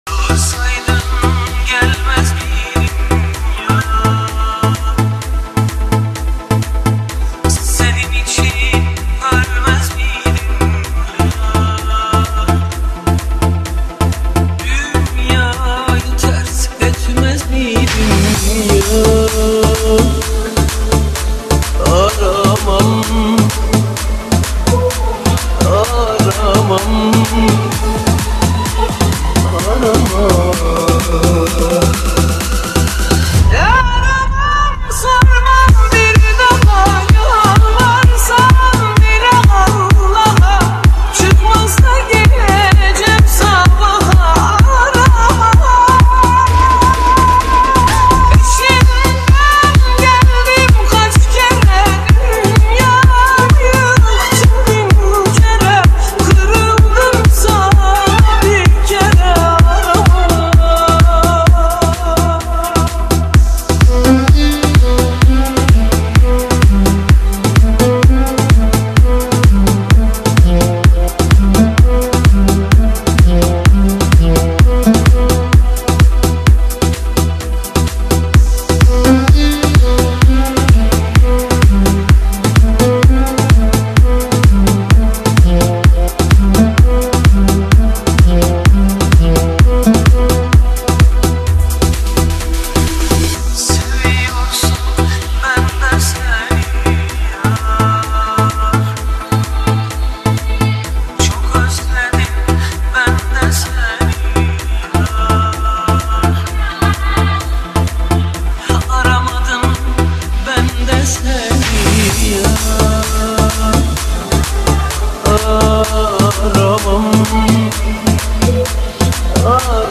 Arabesque, Turkish Folk Music, Pop